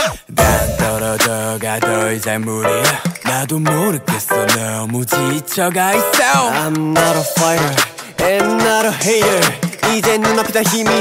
LSL_130_latin_piano_the_knives_Am
LSL_130_latin_perc_loop_marvin_top